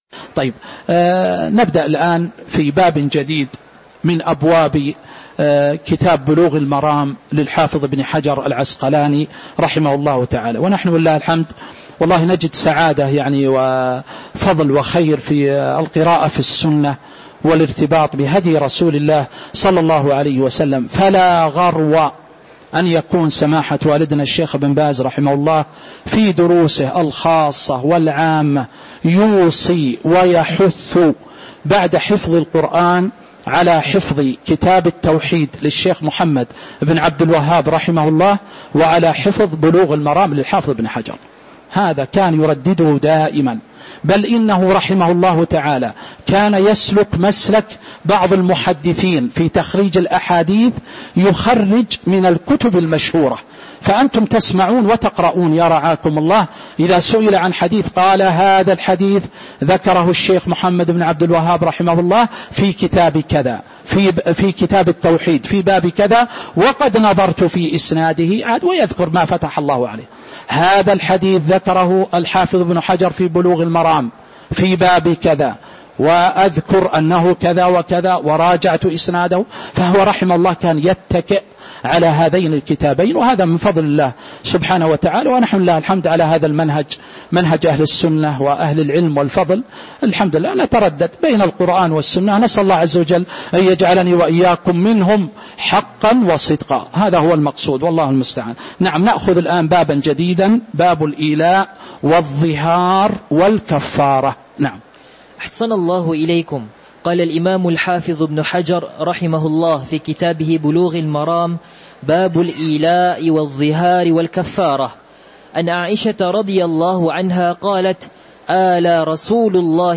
تاريخ النشر ٣٠ شوال ١٤٤٦ هـ المكان: المسجد النبوي الشيخ